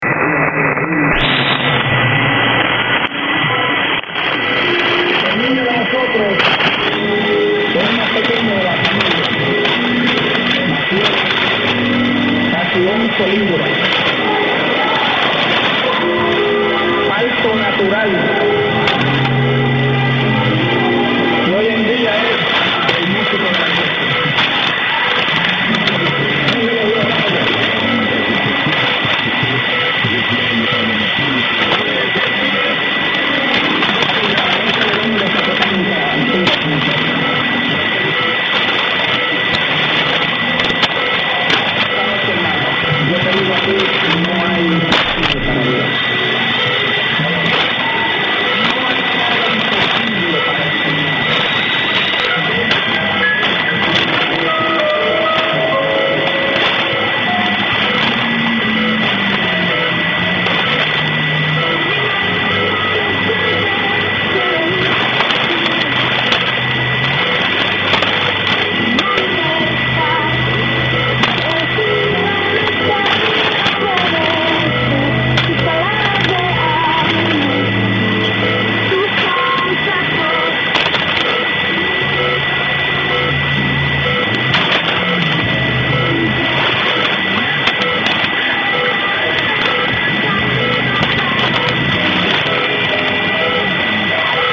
Such was the signal level of the music on 1710 this am, that I have included an audio clip.